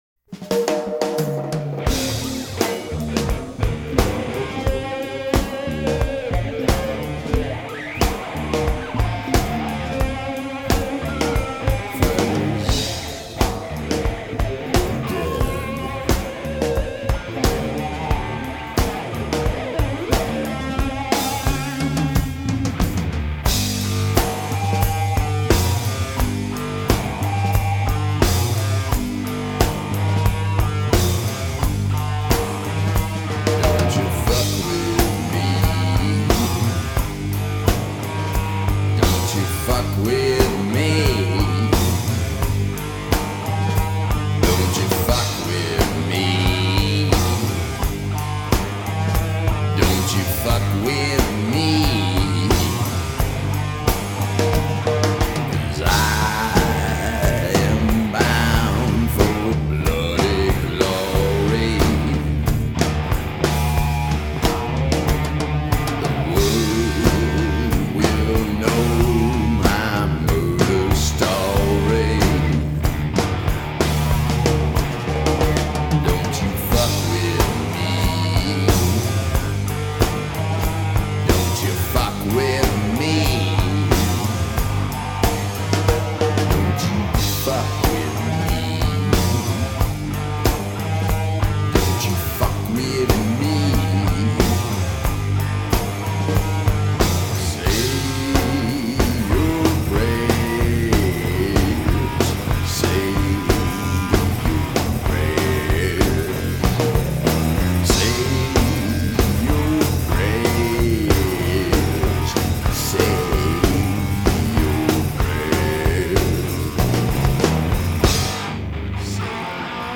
> чтобы с такими же психоделичными гитарными запиламии.